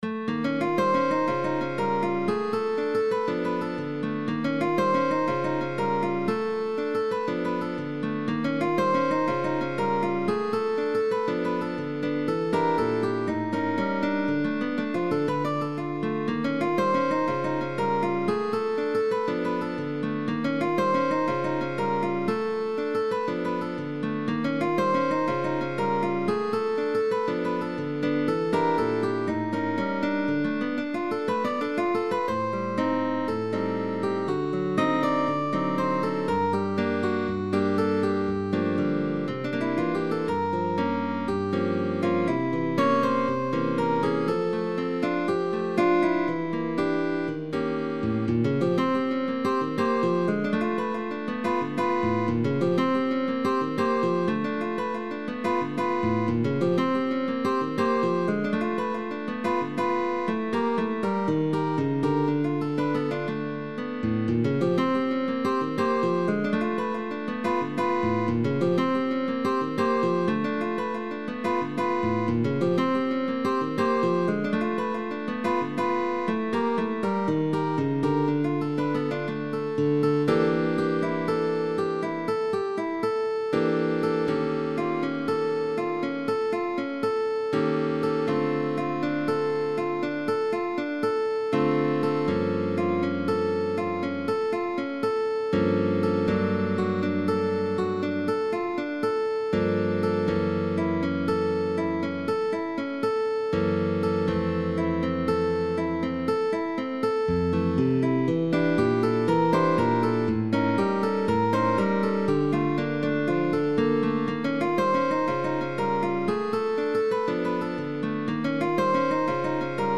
GUITAR DUO Concert Work